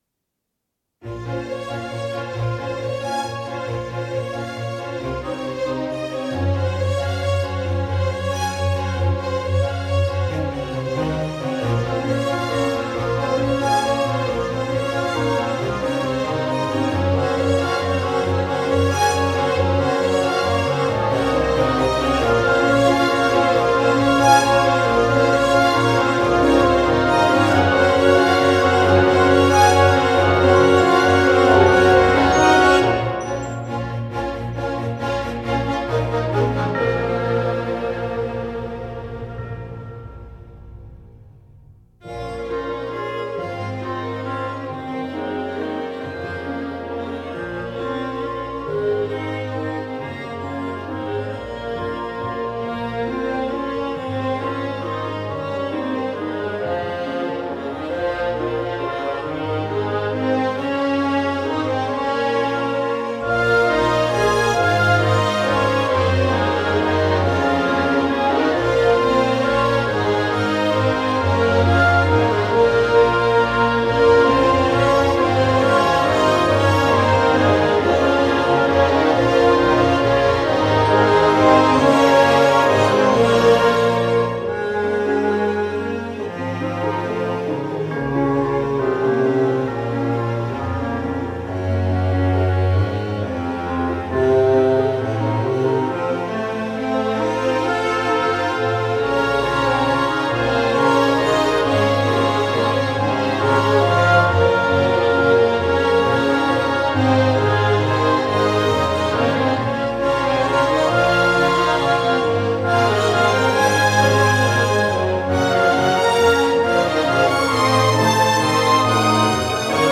• Dropped BPM from 70 to 68.